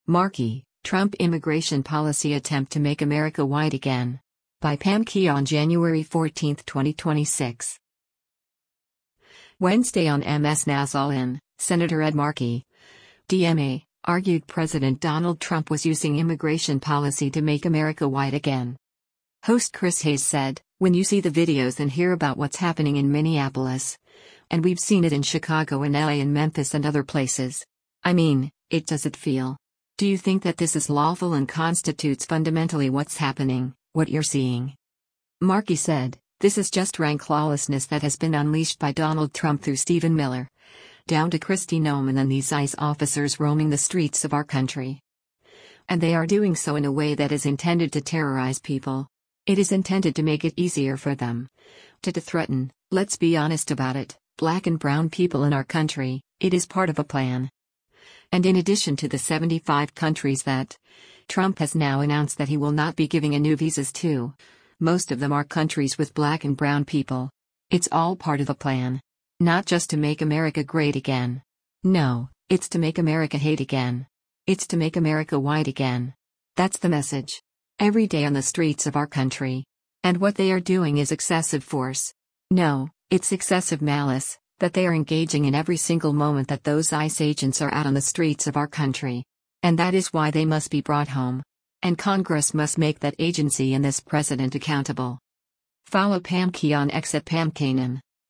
Wednesday on MS NOW’s “All In,” Sen. Ed Markey (D-MA) argued President Donald Trump was using immigration policy to “make America white again.”